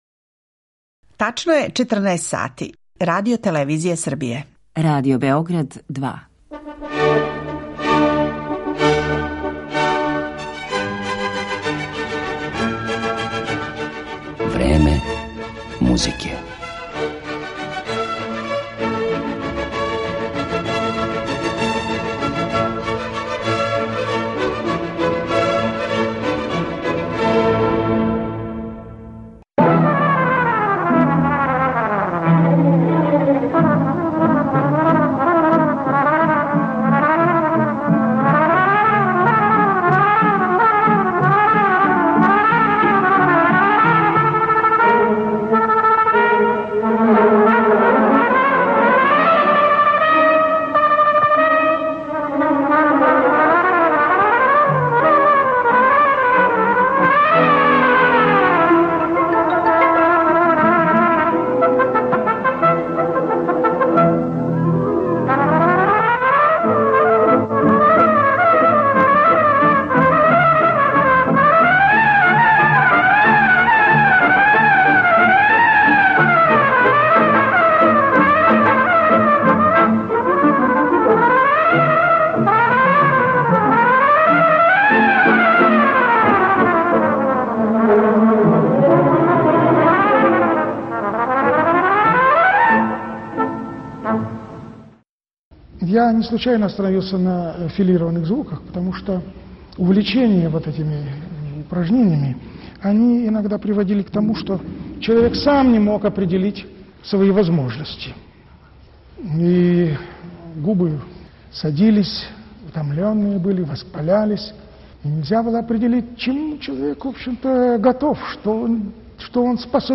Чућете причу о његовој извођачкој, педагошкој и аранжерској каријери, као и одабране снимке његових неупоредивих интерпретација.